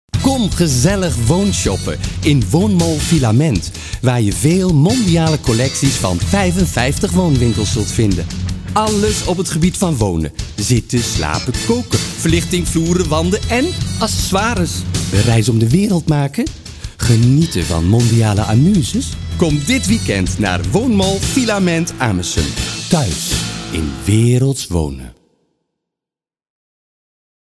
Klankkleur & Stemdemo’s
Empatisch, enthousiast, iemand met een geloofwaardige stem.
Commercial hardselling, Woonshoppen